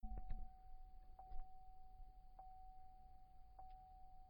Elevator Beeping x4
beep beeping ding elevator sound effect free sound royalty free Sound Effects